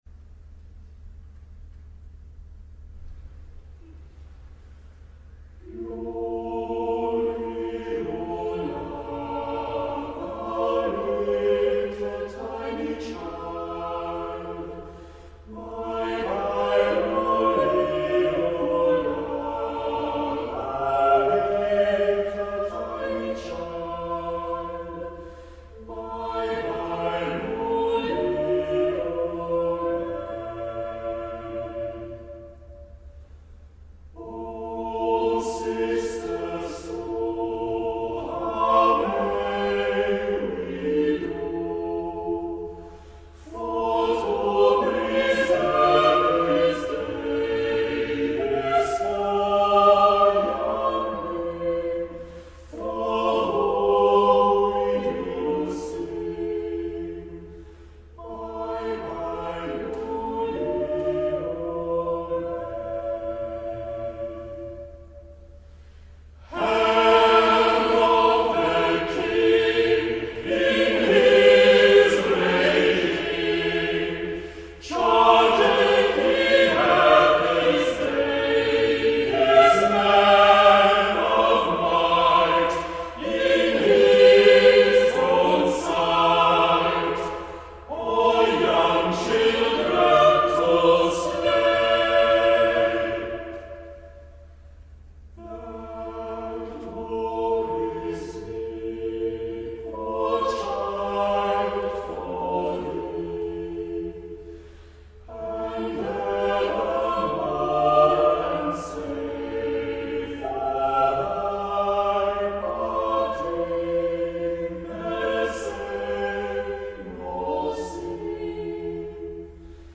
choir.mp3